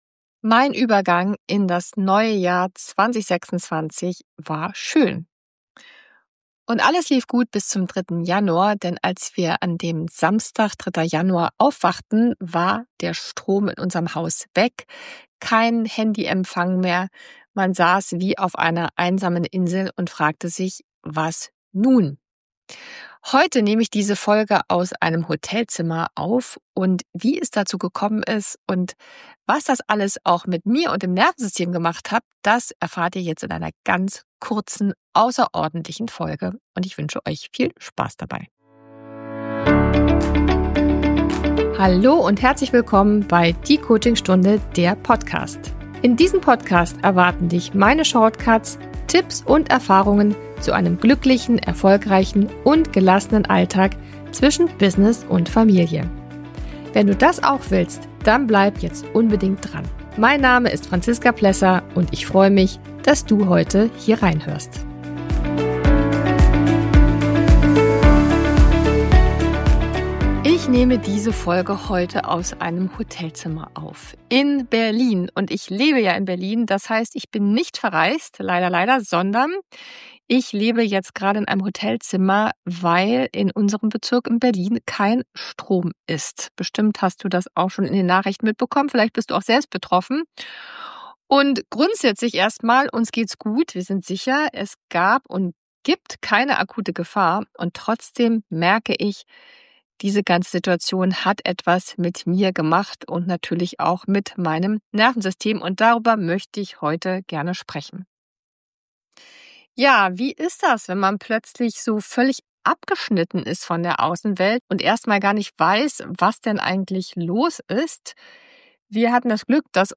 In dieser ganz persönlichen und etwas außergewöhnlichen Folge berichte ich direkt aus einem Hotelzimmer über meine Erfahrungen mit dem mehrtägigen Stromausfall in meinem Bezirk.